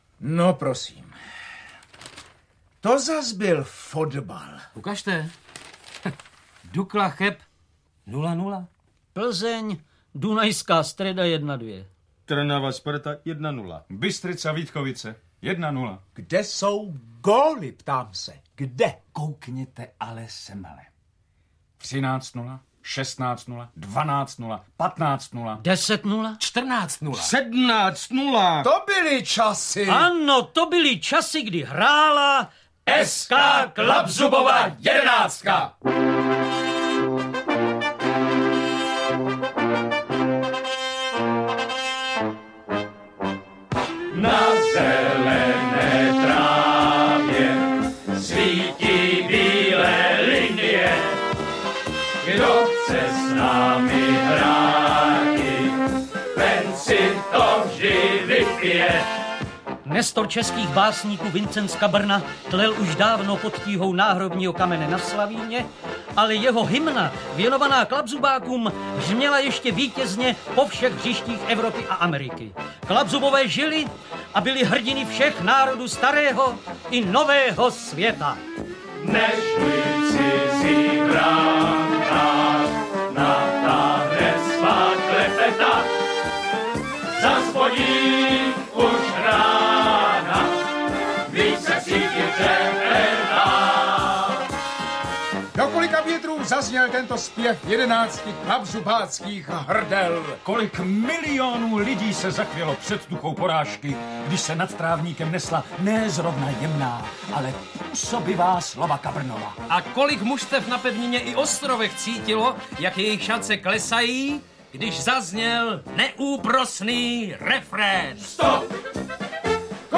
Klapzubova jedenáctka audiokniha
Ukázka z knihy
• InterpretFrantišek Němec, Zdeněk Řehoř, Pavel Soukup, Petr Štěpánek, Svatopluk Skopal, Libuše Havelková, Stanislav Fišer, Hana Brothánková